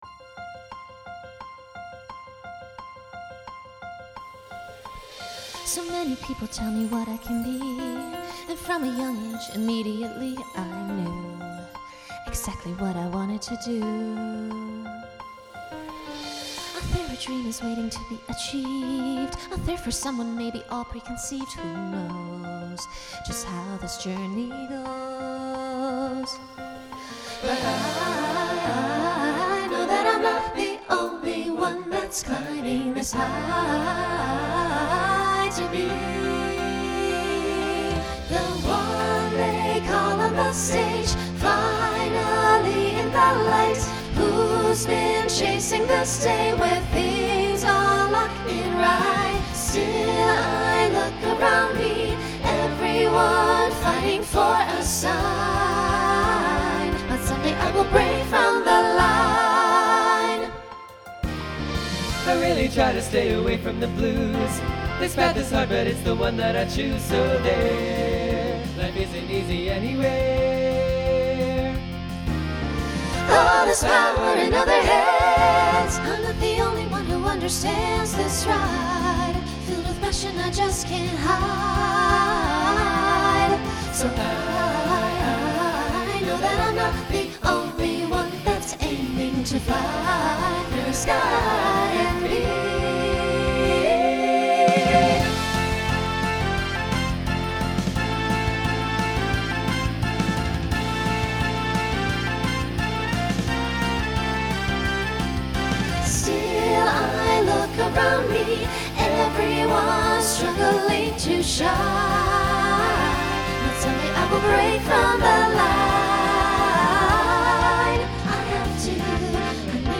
Broadway/Film , Pop/Dance Instrumental combo
Voicing SATB